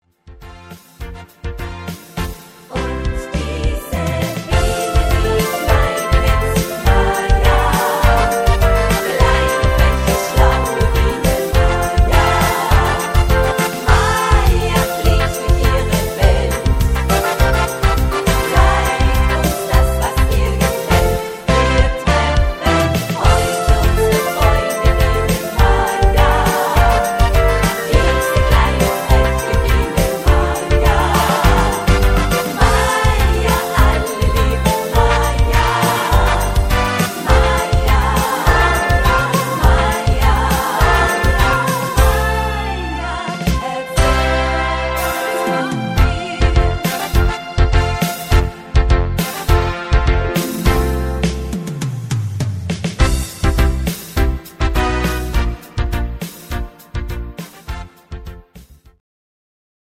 Rhythmus  Quickstep
Art  Deutsch, Party Hits, Schlager 80er